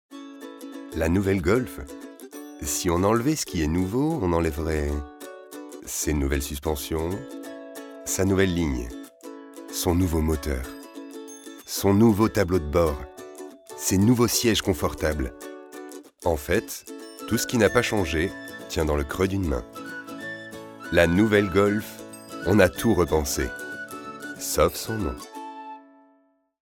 Voix off
fausse pub golf